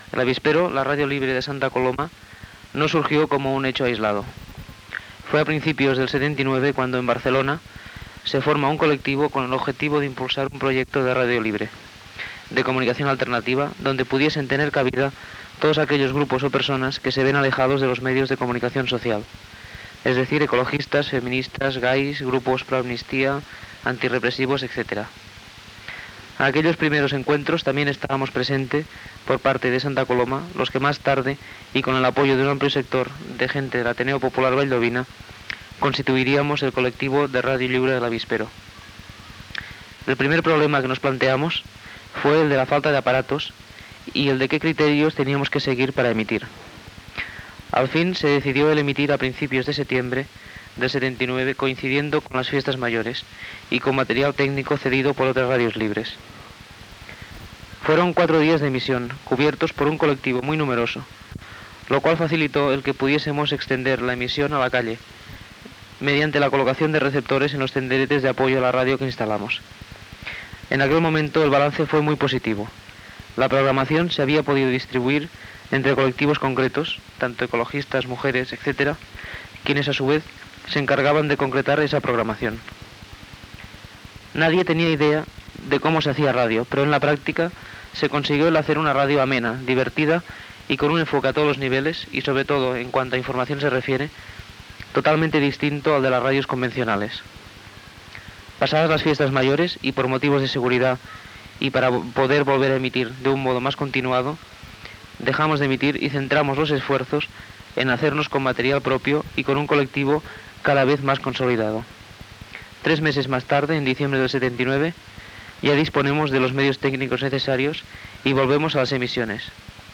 Informatiu
Últim dia d'emissió